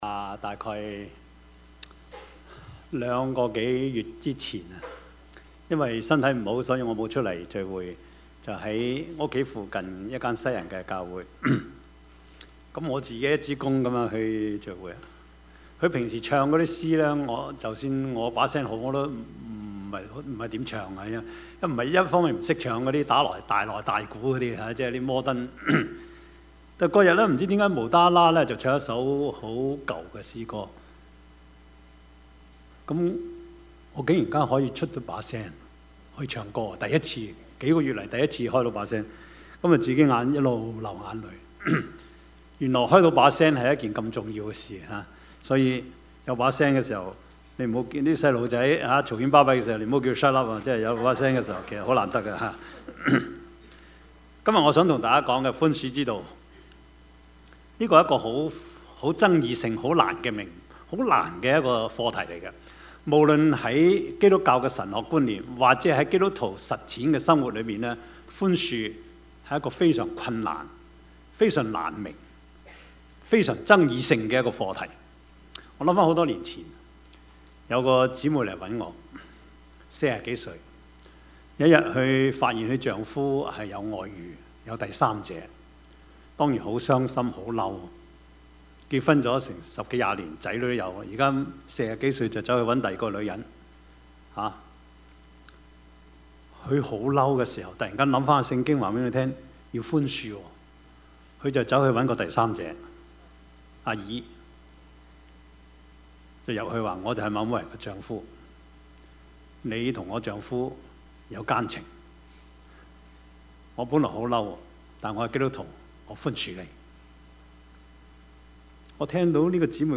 Service Type: 主日崇拜
Topics: 主日證道 « 你來看!